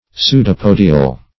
Search Result for " pseudopodial" : The Collaborative International Dictionary of English v.0.48: Pseudopodial \Pseu`do*po"di*al\, a. Of or pertaining to a pseudopod, or to pseudopodia.